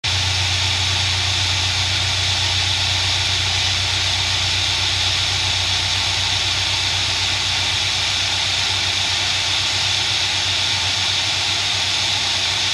Störgeräusche mit Behringer UMC22
Leider wird ein extremes Störsignal dabei erzeugt, das wirklich penetrant ist und bei dem das Noisegate nicht viel hilft, auch im Internet habe ich leider nicht viel dazu gefunden.
Im Anhang ist ein eine Datei mit dem Rauschen, wenn ich das Noisegate ausmache.
Signalkette ist lediglich meine E-Gitarre (an der kanns nicht liegen, bei den anderen Gitarren ists genau so) in das Behringer, und das Behringer via USB in den Laptop, der grade sogar auch noch ohne Netzteil läuft, daran kanns dann denke ich auch nicht liegen.